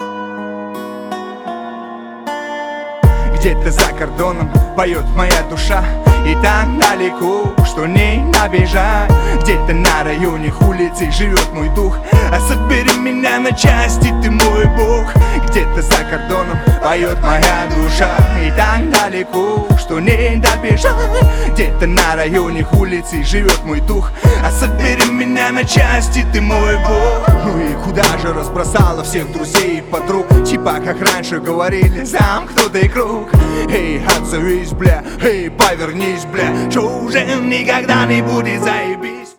• Качество: 320, Stereo
грустные
русский рэп